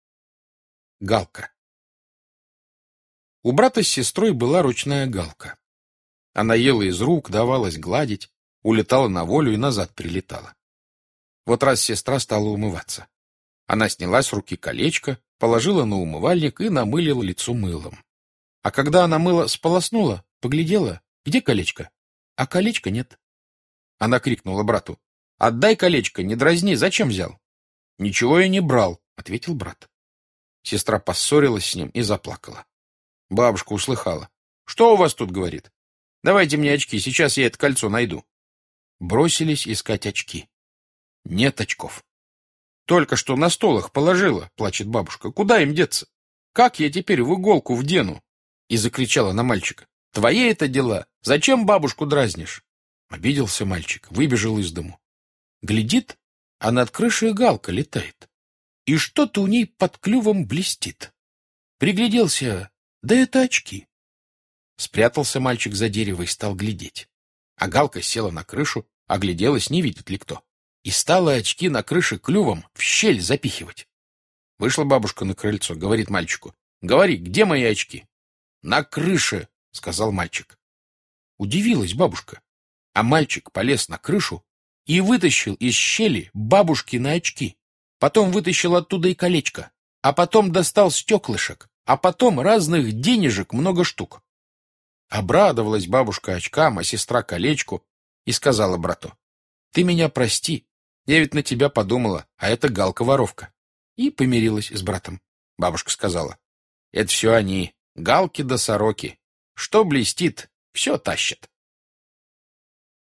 Галка - аудио рассказ Житкова Б.С. Была в одной семье ручная галка.